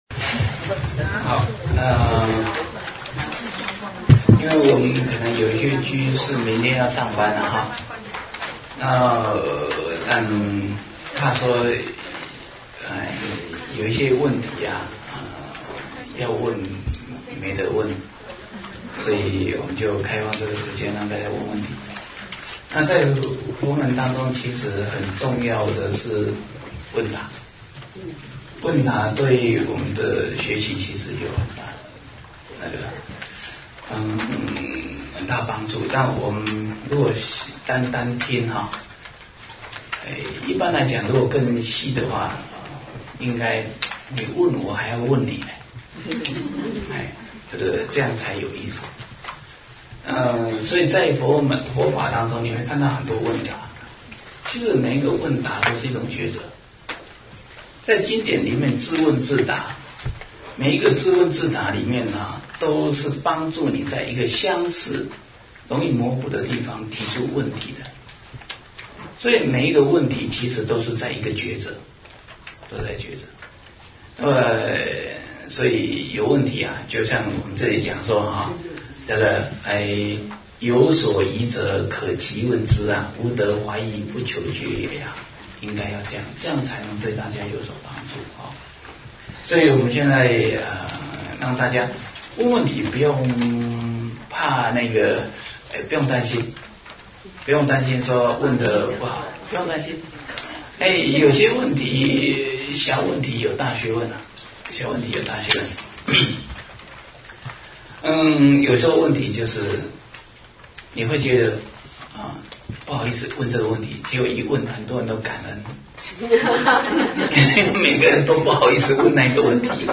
念佛法门与四圣谛10(问答).mp3